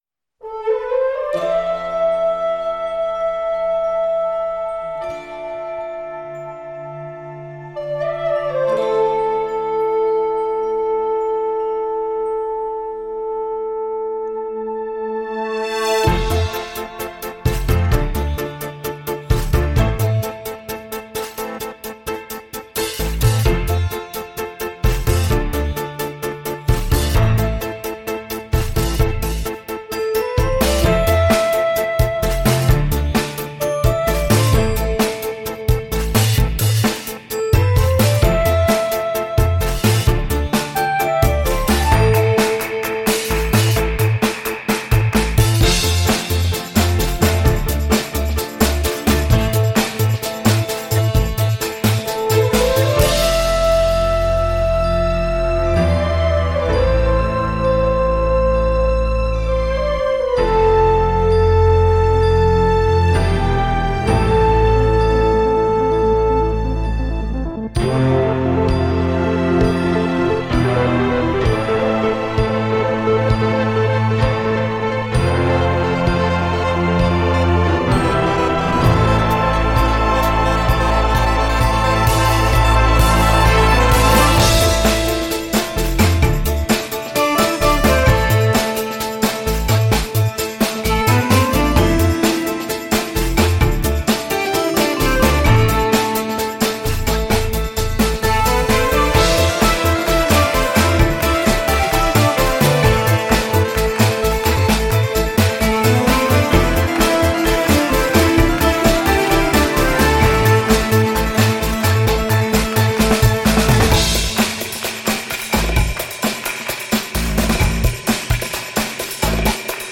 Et surtout, c’est fun et rock’n roll.